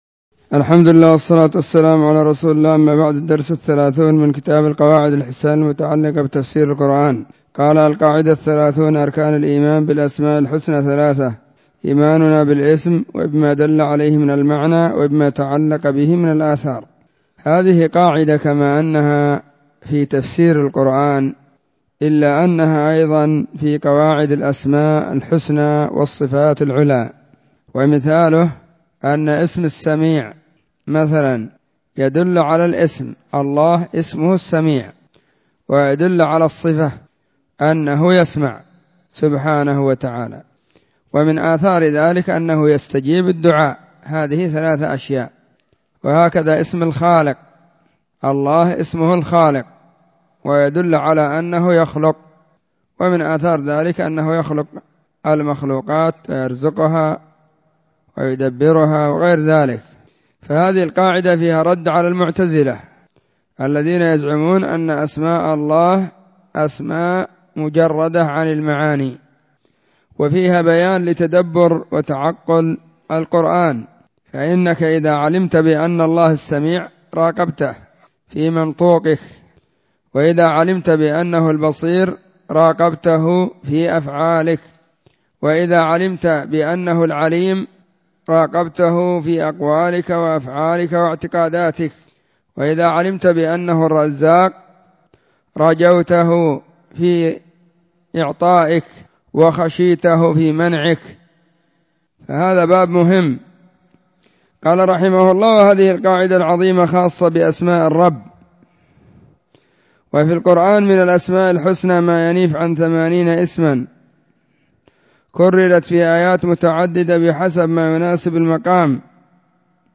🕐 [بعد صلاة الظهر في كل يوم الخميس]
📢 مسجد الصحابة – بالغيضة – المهرة، اليمن حرسها الله.